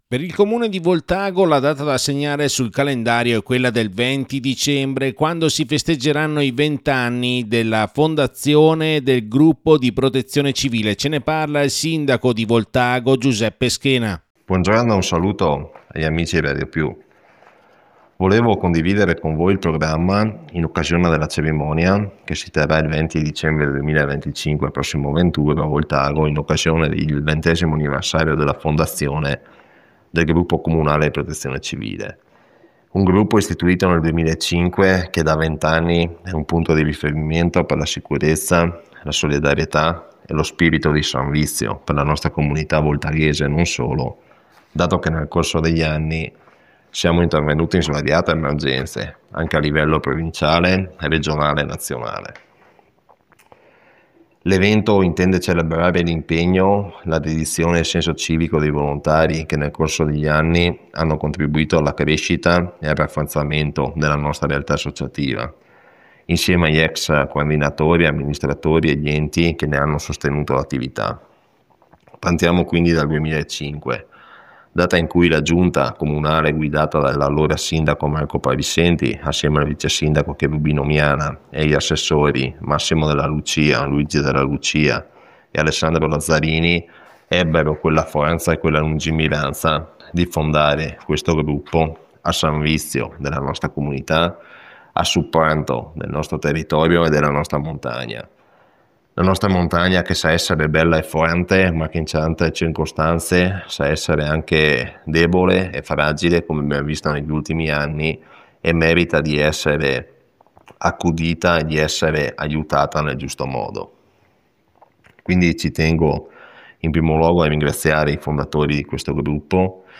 OSPITE: Giuseppe Schena, Sindaco di Voltago